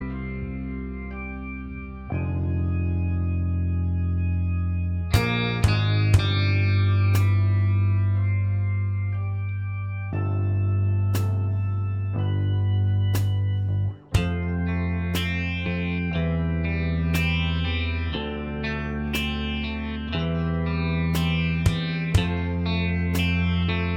Minus Lead Guitar Soft Rock 4:46 Buy £1.50